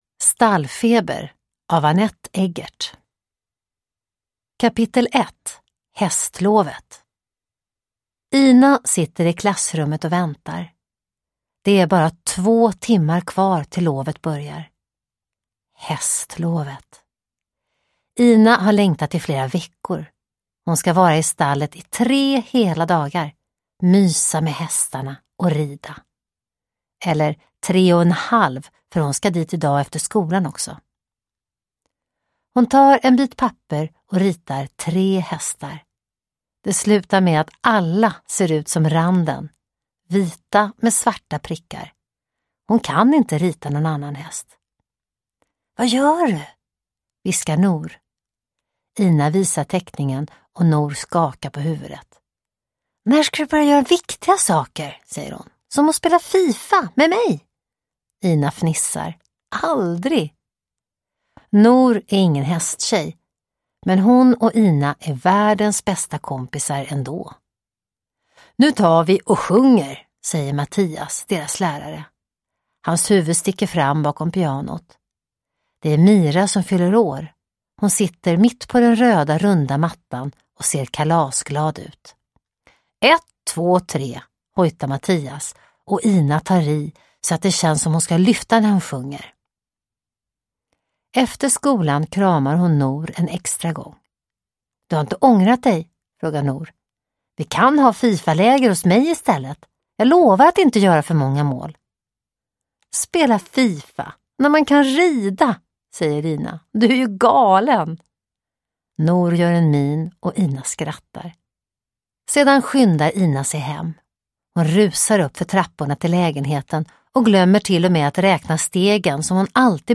Ina Scot - Stallfeber (ljudbok) av Anette Eggert